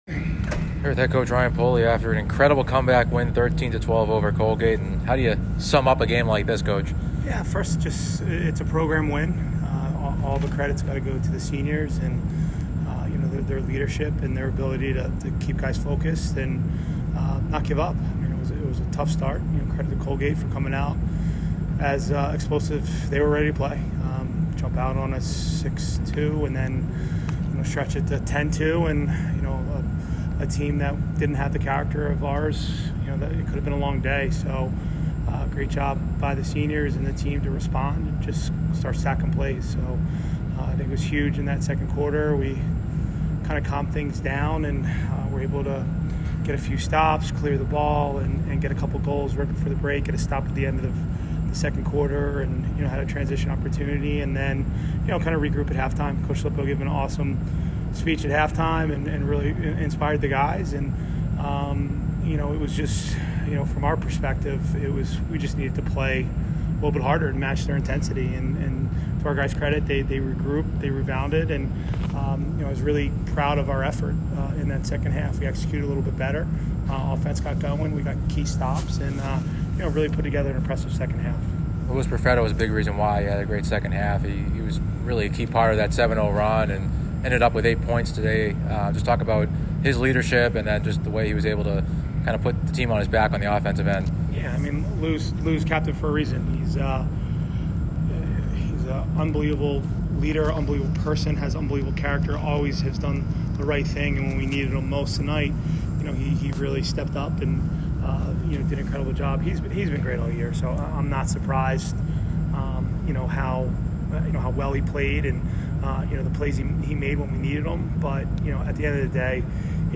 Colgate Postgame Interview